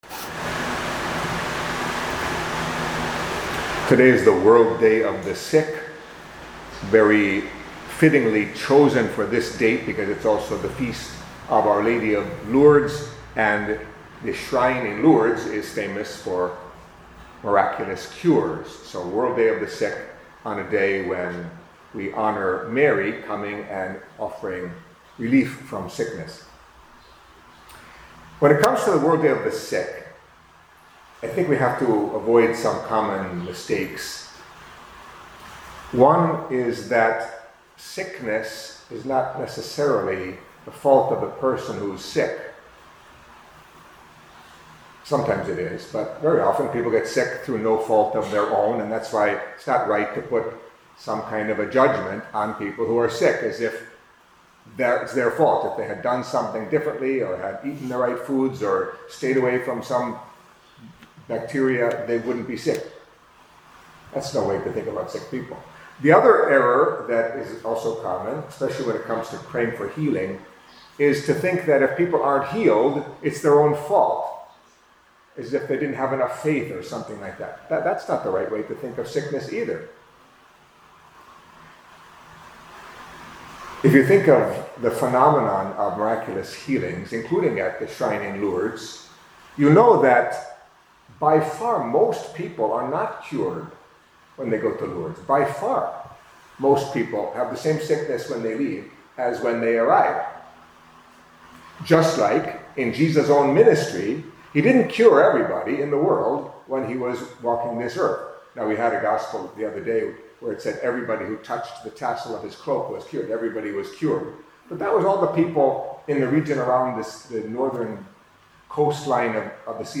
Catholic Mass homily for Wednesday of the Fifth Week in Ordinary Time